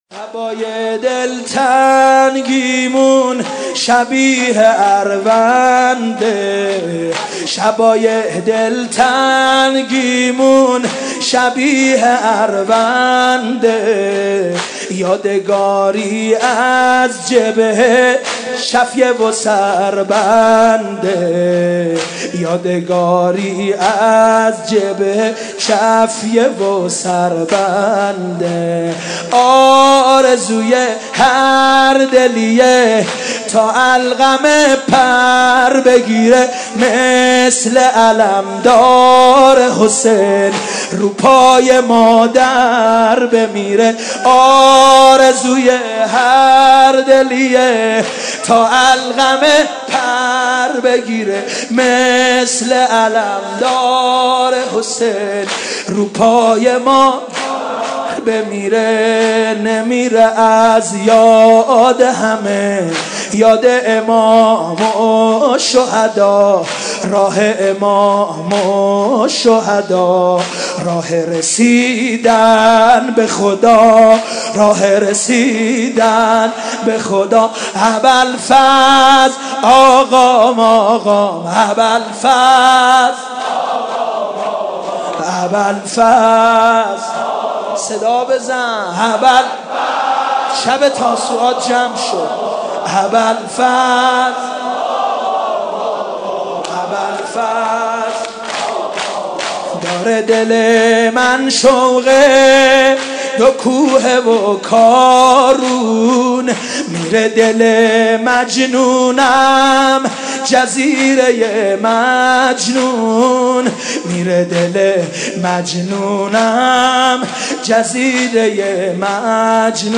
گلف چند رسانه‌ای مداحی صوت هوای دلتنگیمون شبیه ارونده هوای دلتنگیمون شبیه ارونده مرورگر شما از Player پشتیبانی نمی‌کند.